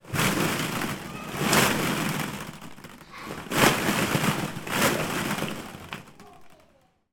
Шум шаров в бассейне детской площадки